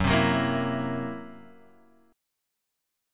Gb7b5 chord